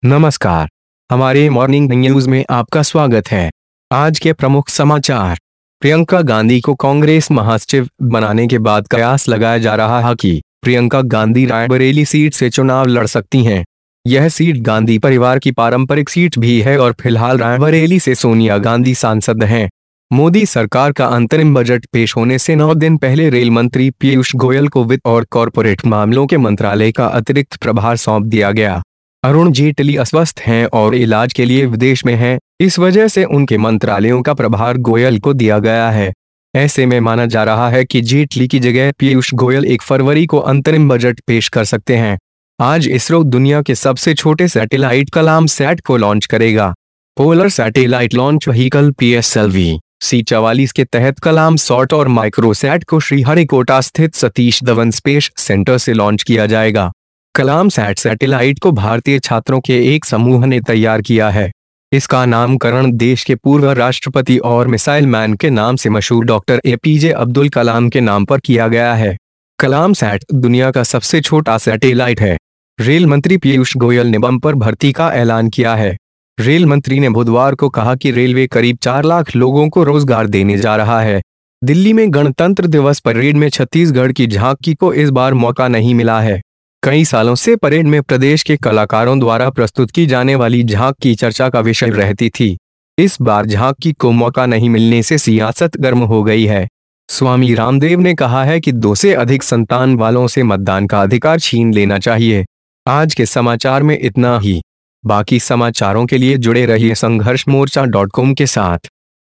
आज 24 जनवरी को मॉर्निंग न्यूज़ में सुने प्रियंका गांधी लड़ेंगी रायबरेली से चुनाव,अब जेटली की जगह पीयूष गोयल पेश करेंगे बजट